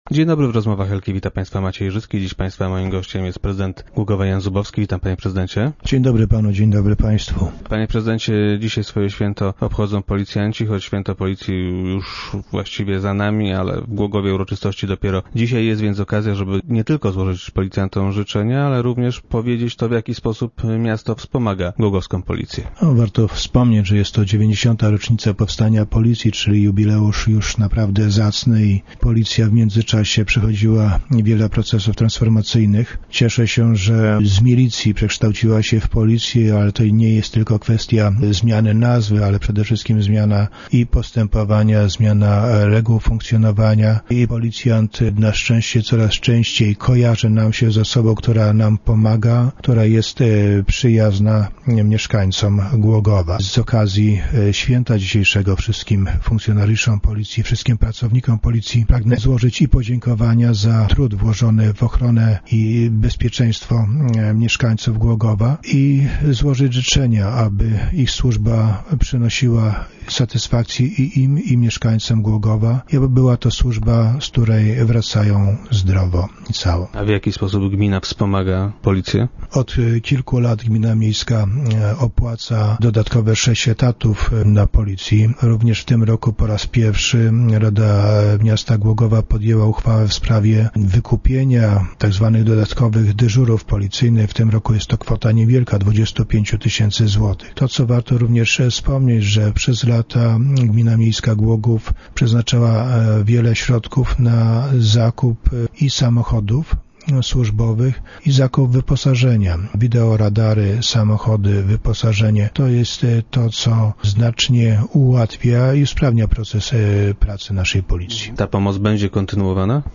Prezydent Zubowski zapowiedział na radiowej antenie, że miasto nadal będzie wspomagało funkcjonariuszy.
- Z okazji dzisiejszego święta, wszystkim policjantom i pracownikom policji pragnę złożyć podziękowania za trud włożony o ochronę bezpieczeństwa mieszkańców Głogowa. Chcę im też złożyć życzenia, by służba przynosiła im satysfakcję - życzył prezydent Jaz Zubowski, który był dziś gościem Rozmów Elki.